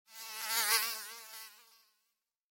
Zvuk-komara-kotoryy-proletel-mimo
• Kategoria: Dzwoniące komary